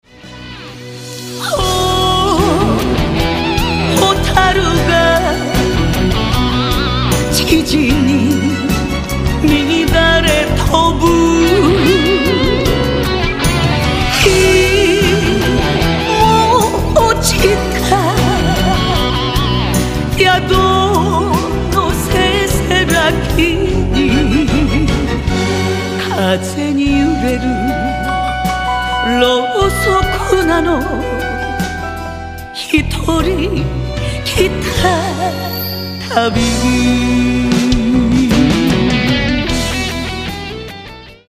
３．歌入り